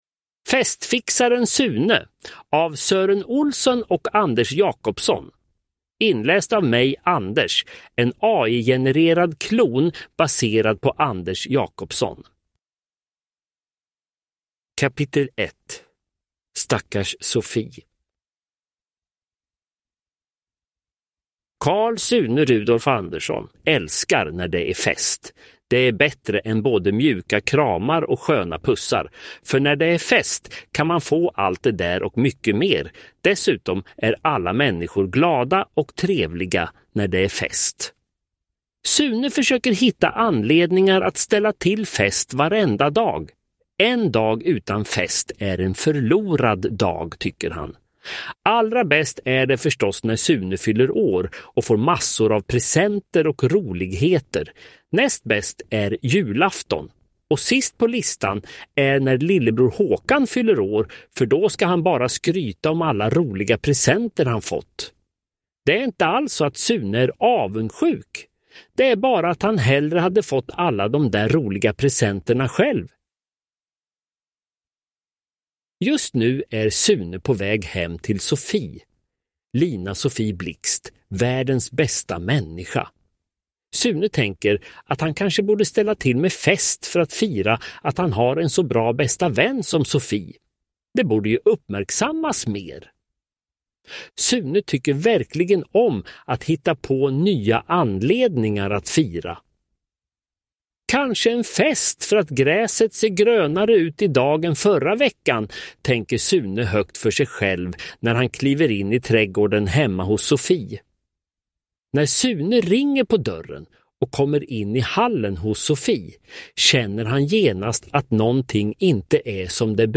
Festfixaren Sune – Ljudbok
Uppläsare: Anders Jacobsson AI